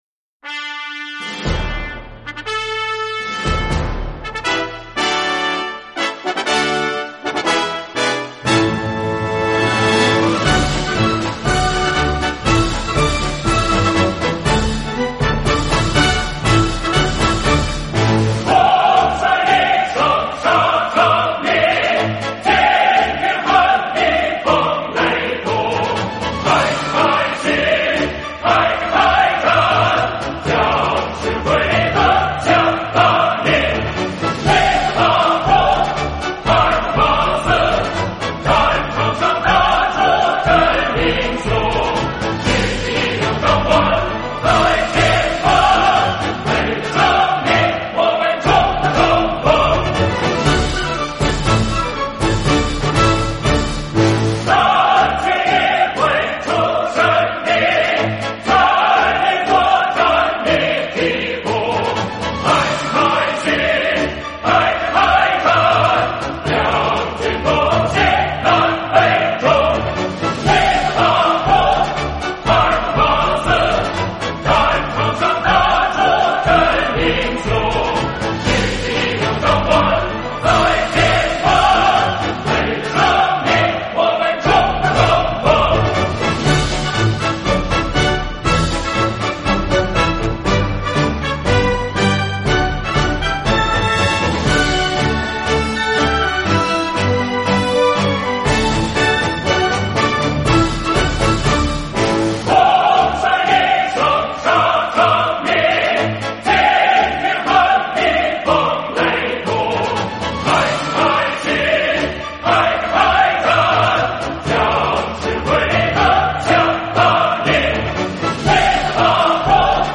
演唱：合唱